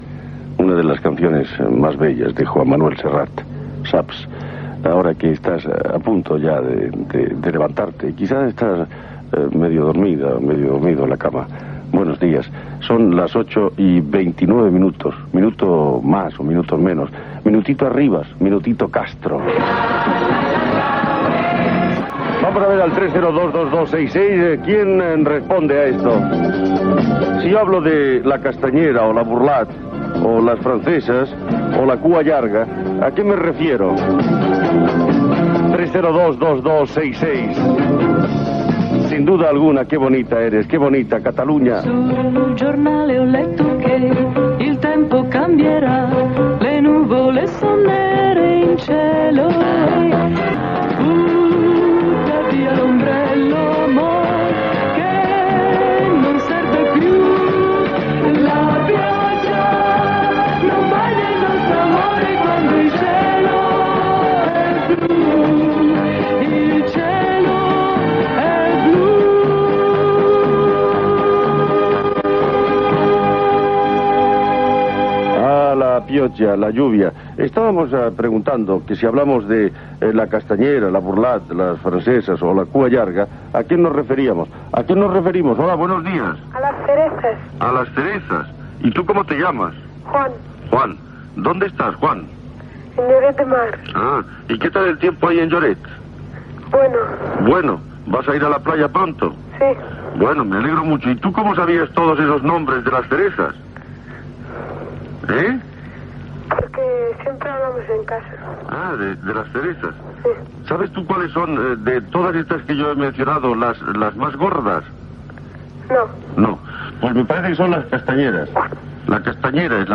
Comentari del tema musical que acaba, hora, pregunta a l'audiència sobre les cireres, tema musical, trucada d'un oïdor per contestar la pregunta, hora, temes musicals i improvisacions poètiques.
Entreteniment